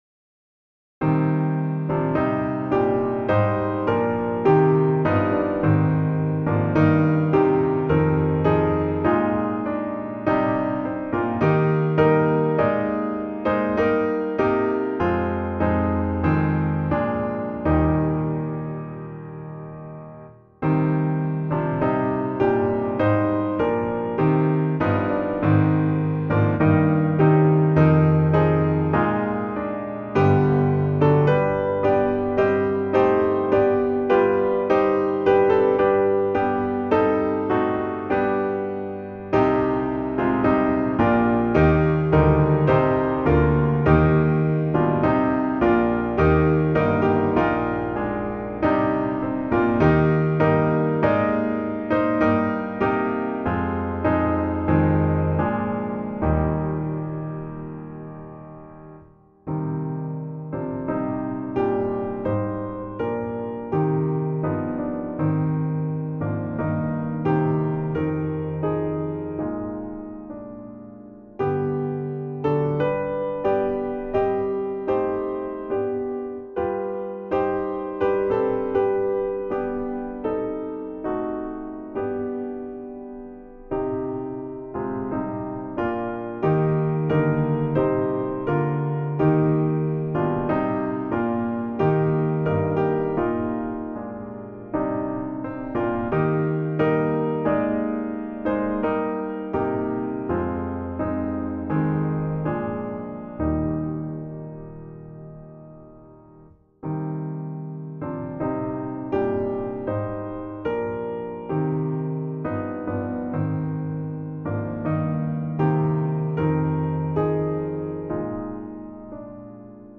Choir Unison, SATB